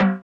Timbale Groovin.wav